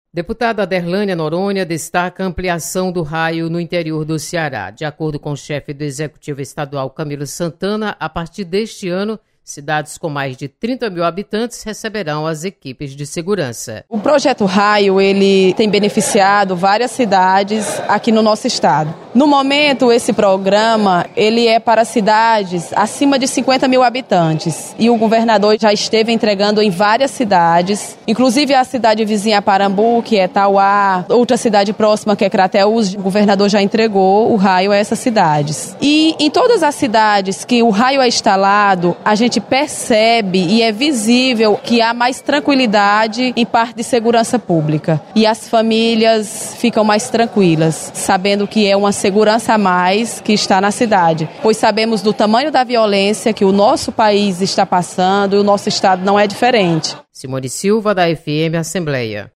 Deputada Aderlânia Noronha destaca ampliação do Raio nas cidades do Interior. Repórter